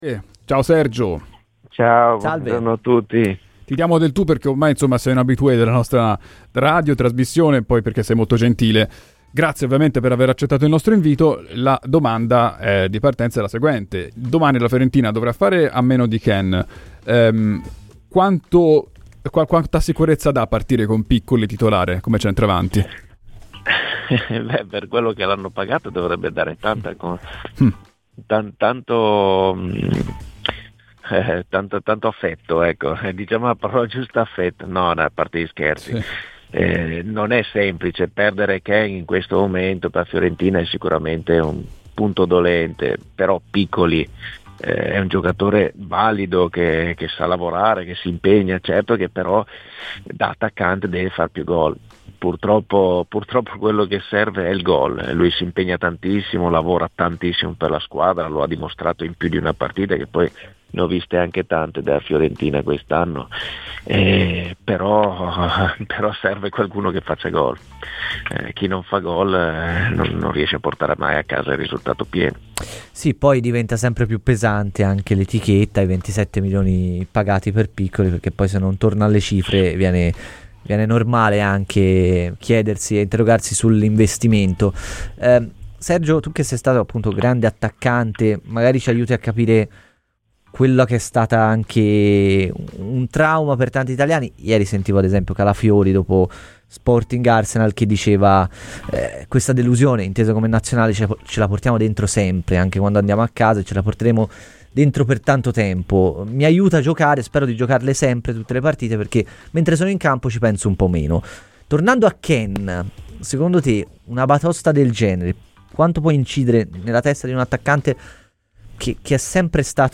Nel corso dei "Tempi Supplementari", su Radio FirenzeViola, spazio a Sergio Pellissier.